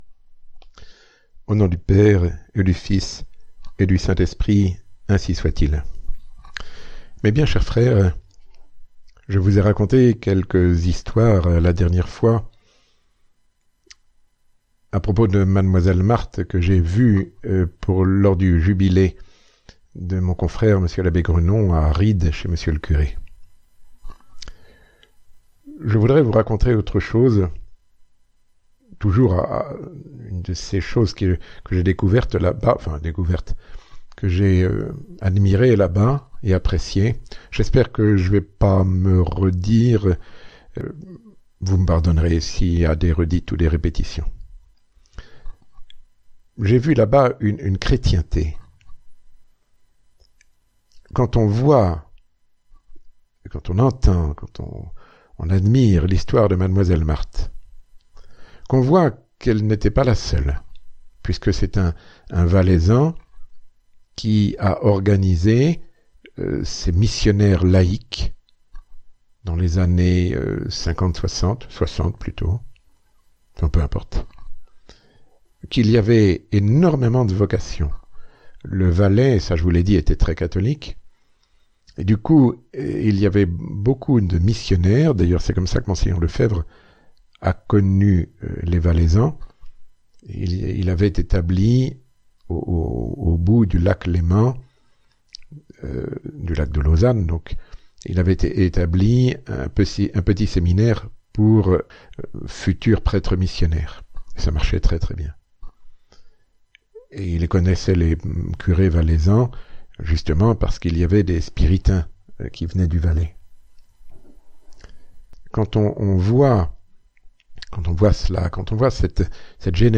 Sermon ~ Le Sacré-Cœur, ce qu’est une chrétienté